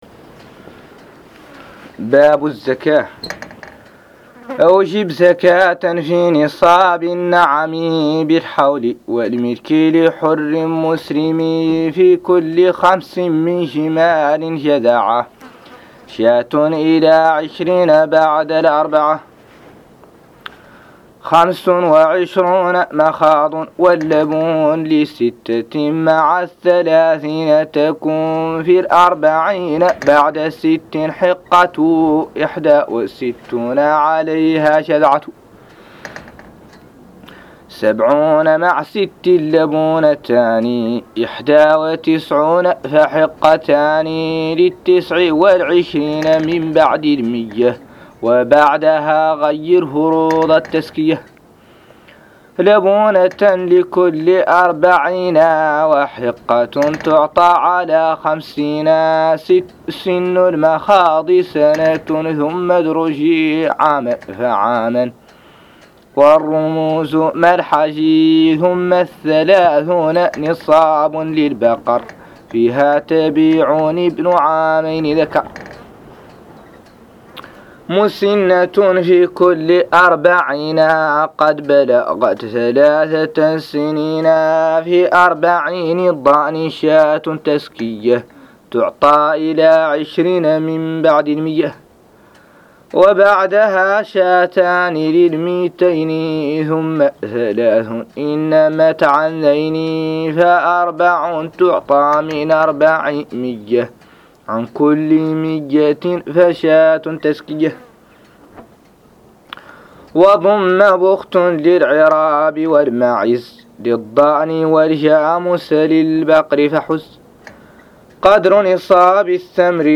قراءة منهاج السالك 03